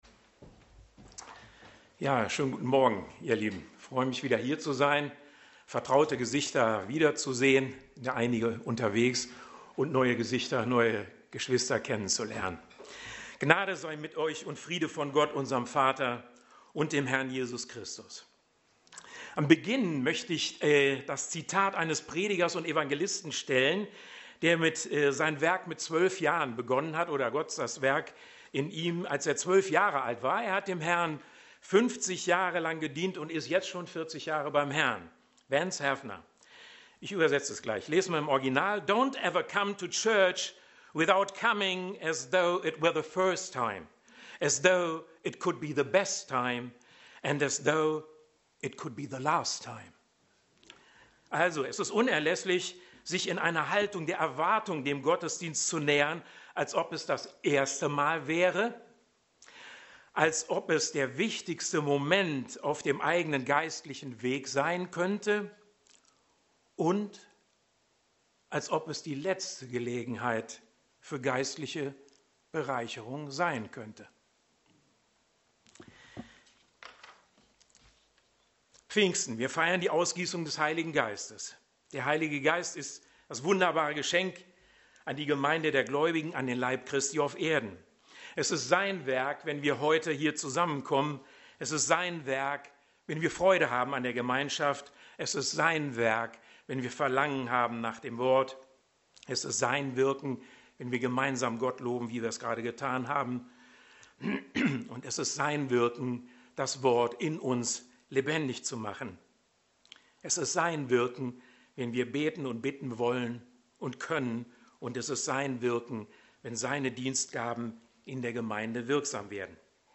Passage: Lukas 4,14-22 Dienstart: Predigt